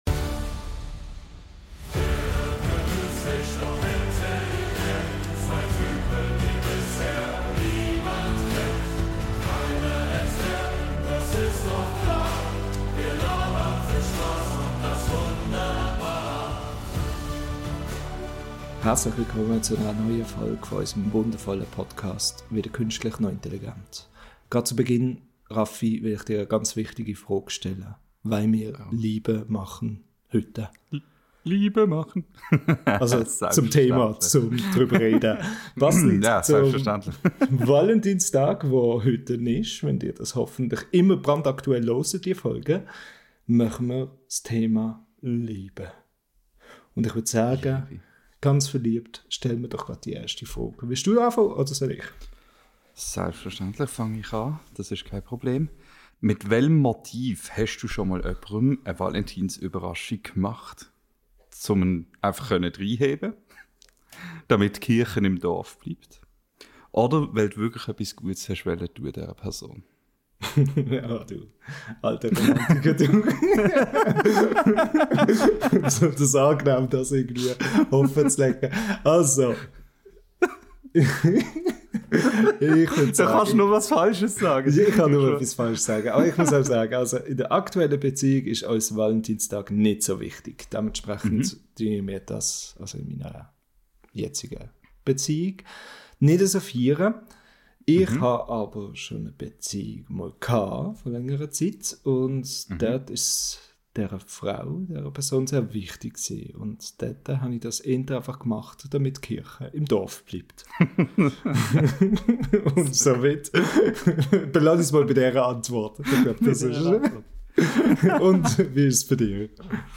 In diesem Valentinstags-Special unseres schweizerdeutschen Podcasts dreht sich alles um die Liebe!